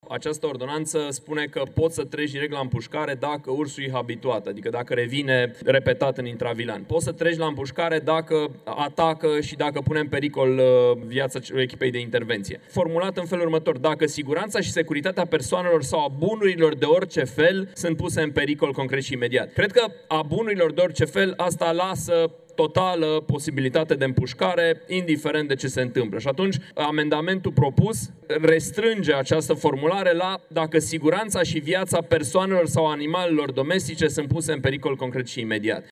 Alen Coliban, deputat USR: „Această ordonanță spune că poți să treci direct la împușcare dacă ursul este habituat”